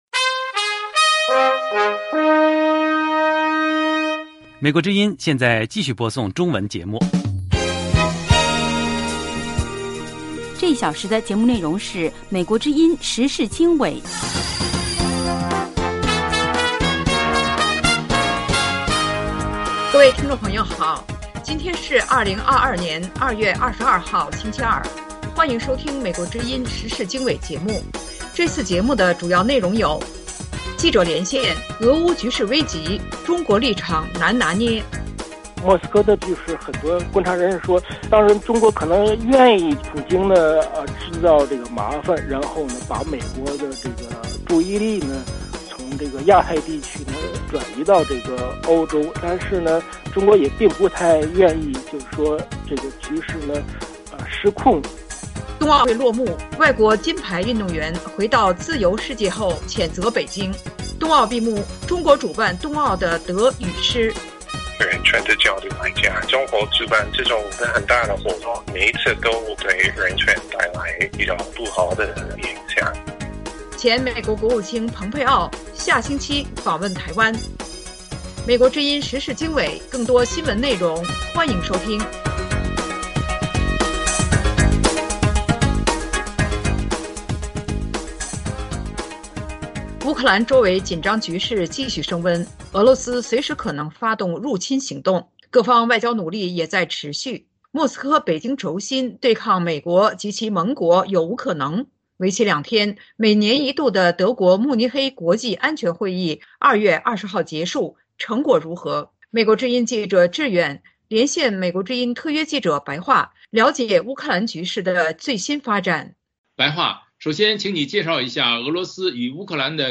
时事经纬(2022年2月22日) - 记者连线：俄乌局势危急，中国立场难拿捏;冬奥会落幕 外国金牌运动员回到自由世界后谴责北京